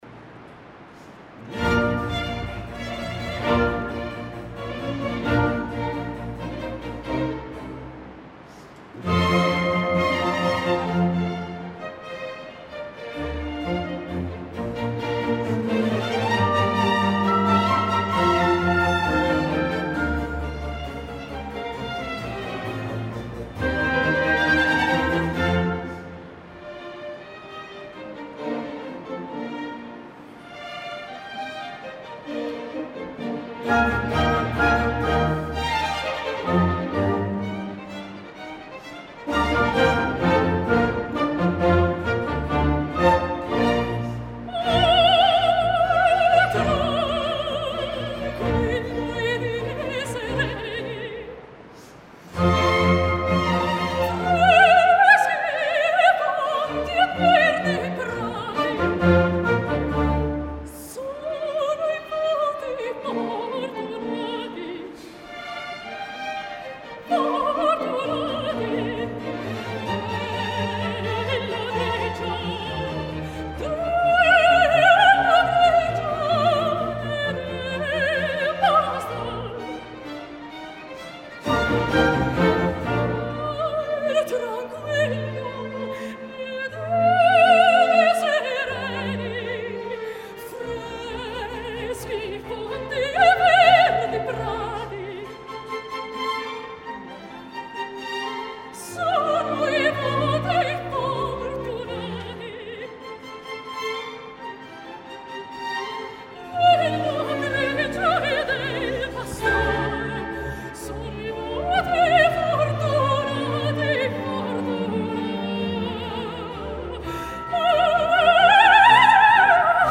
Escoltareu clarament la tempesta, no és una deficiència de so de la gravació.
Verbier Festival Chamber Orchestra
versió de concert
2 d’agost de 2014 Salle des Combins (Verbier, Suïssa)